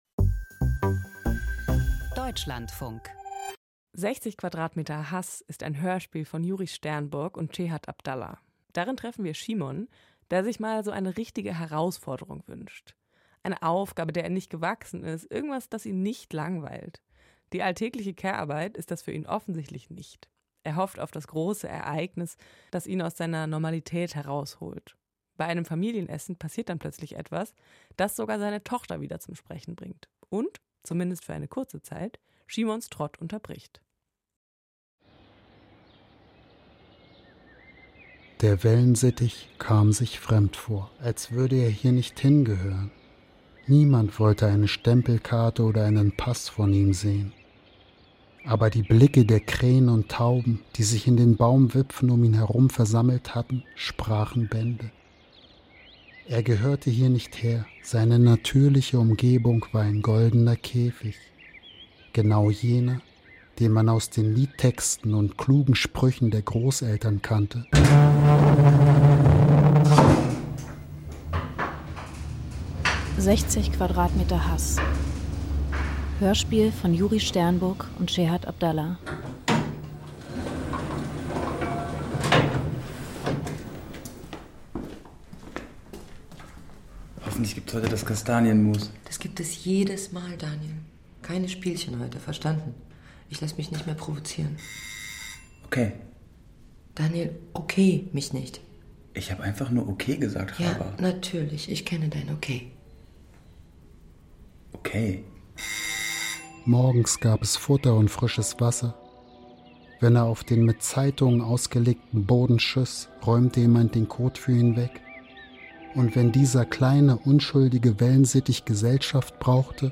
Krimi-Hörspiel: Eine Mutter im Frauengefängnis - Eisen